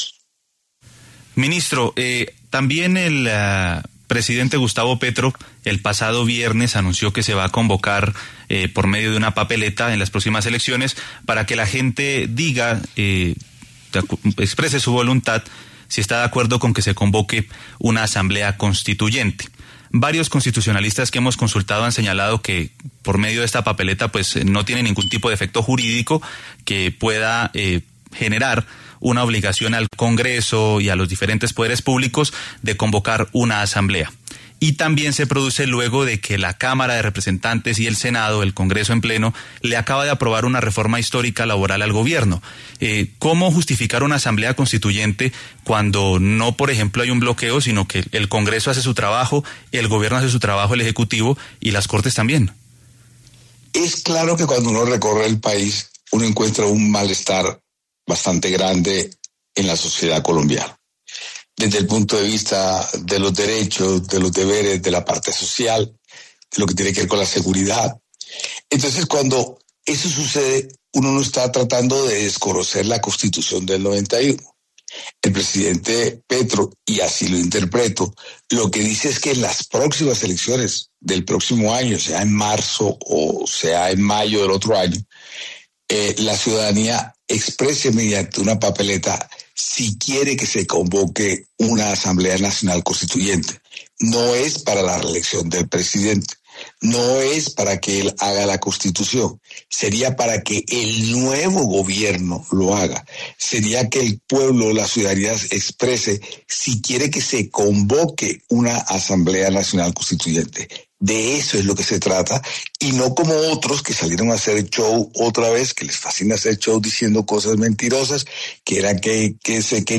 Ante esto, el ministro del Interior, Armando Benedetti, pasó por los micrófonos La W para hablar sobre los hechos.
Armando Benedetti, ministro del Interior, habla en La W